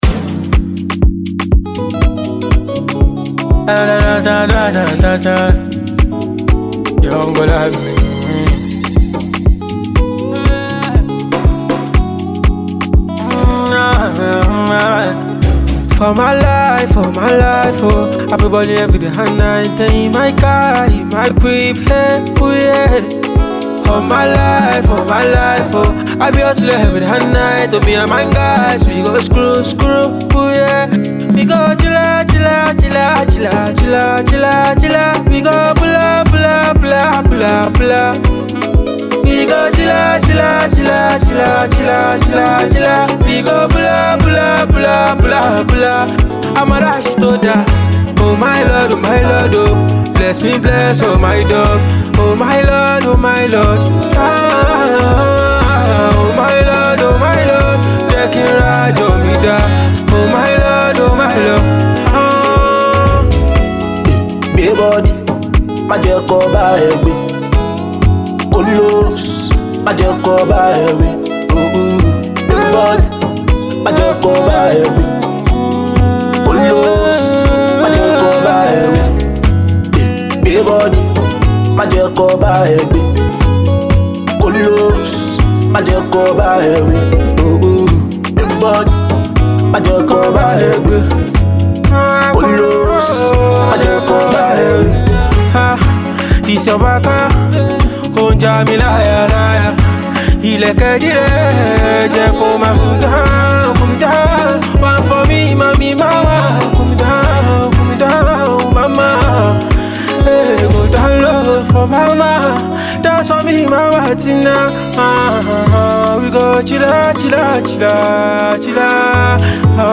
Afropop freestyle single